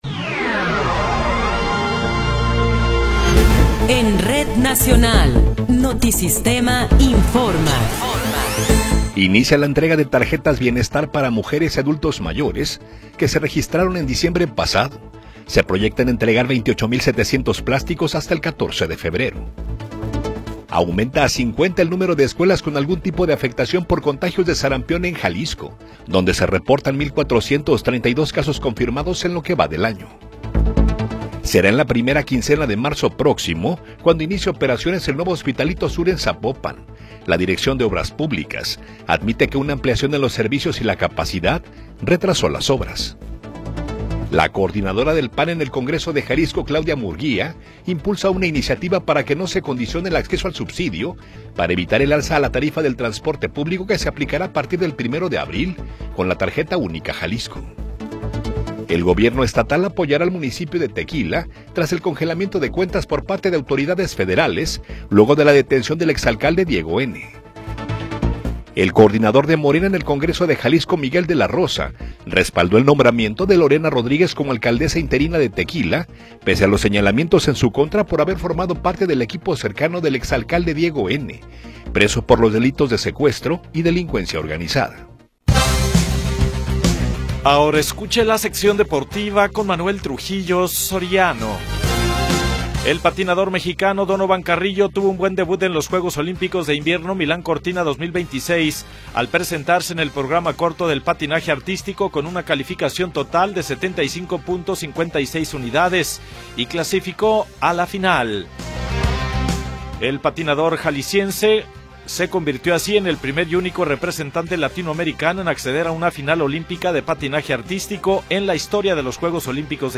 Noticiero 21 hrs. – 10 de Febrero de 2026
Resumen informativo Notisistema, la mejor y más completa información cada hora en la hora.